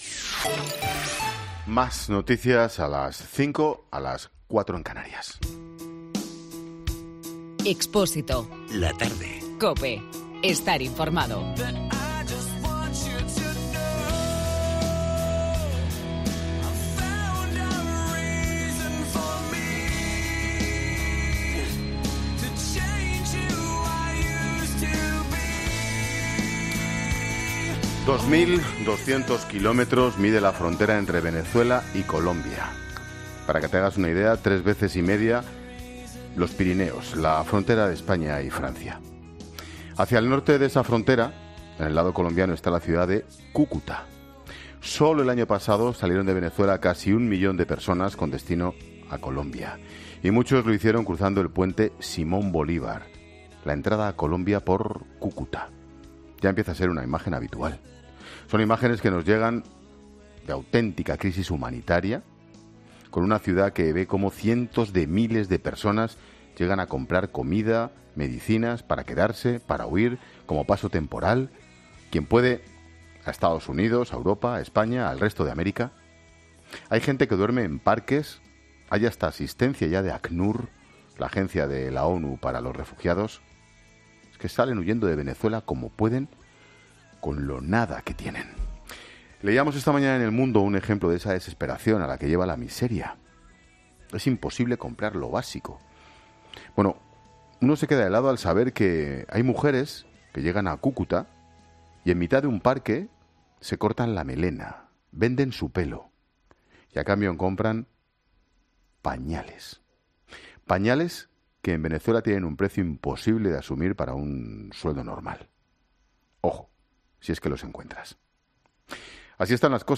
Hablamos en 'La Tarde' con Monseñor Juan de Dios Peña, obispo venezolano, sobre la situación en su país
ESCUCHA LA ENTREVISTA COMPLETA | Monseñor Juan de Dios Peña en 'La Tarde' Los más perjudicados en estos momentos son los jóvenes, que no tienen un futuro claro.